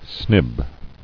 [snib]